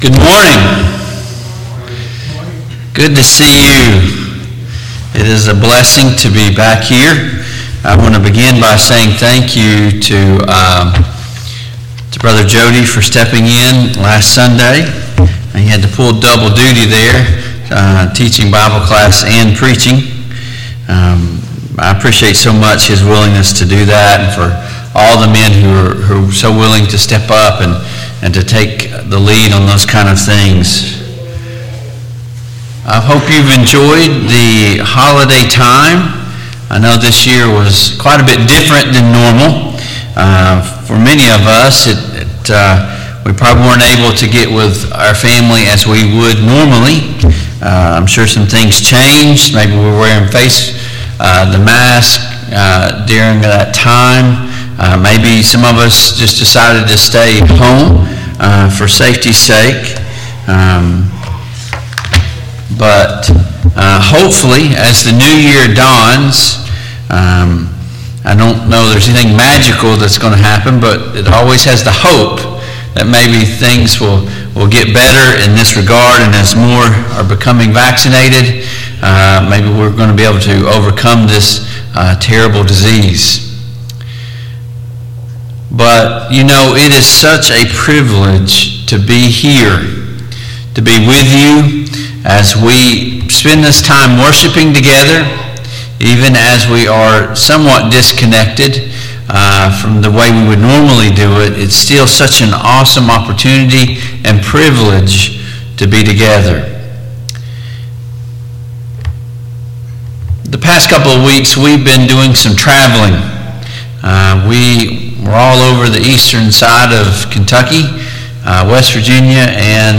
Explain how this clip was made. Service Type: AM Worship Topics: The Word of God